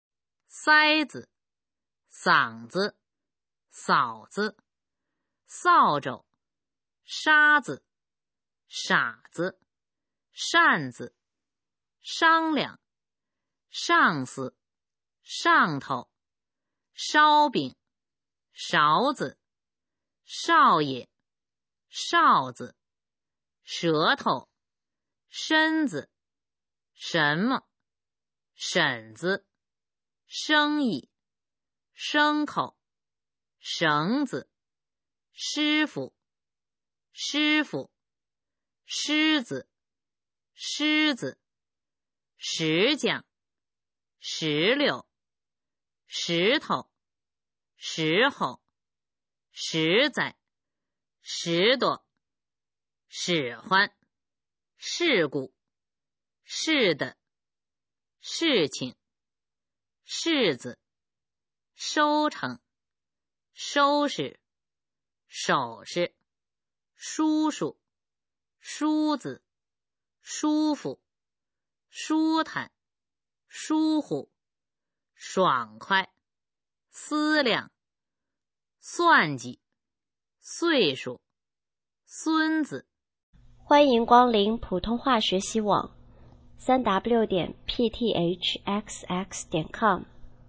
普通话水平测试用必读轻声词语表示范读音第351-400条